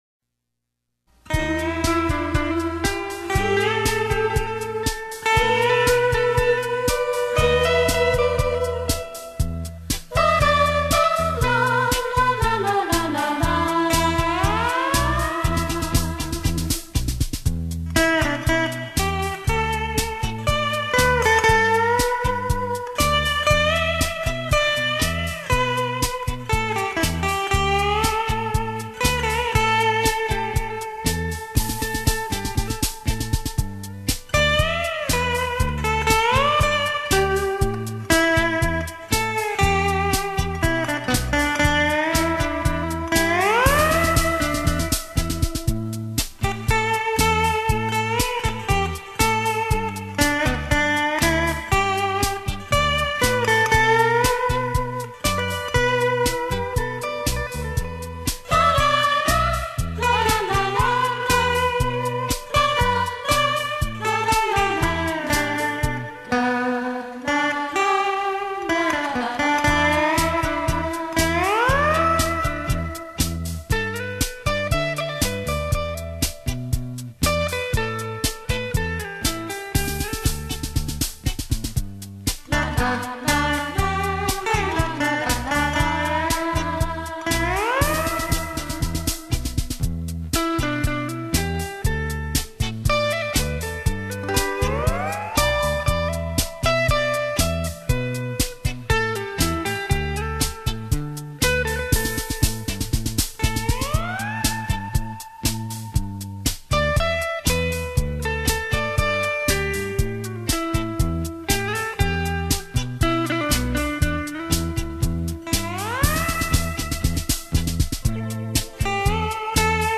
优美的琴声，令人心旷神怡的遐想......